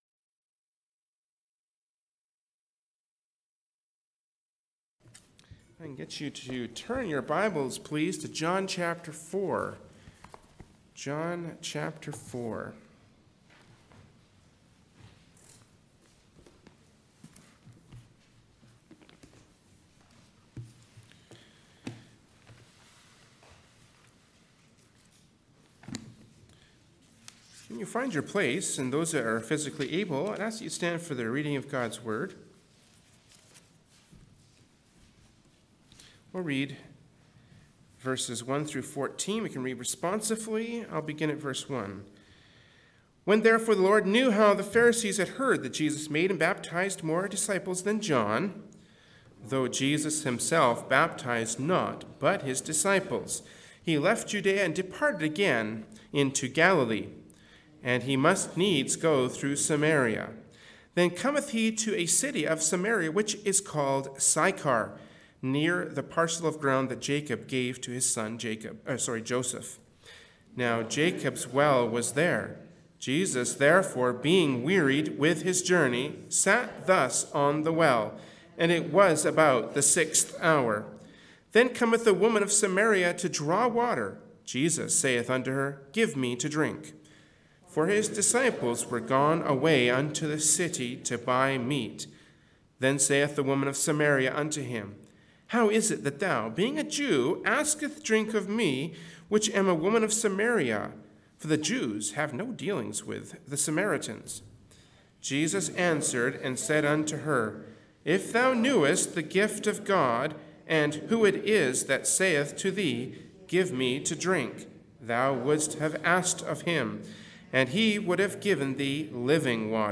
Genre: Preaching.
Passage: John 4:1-14 Service Type: Sunday Morning Worship Service